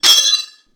katana-clash1
Tags: sword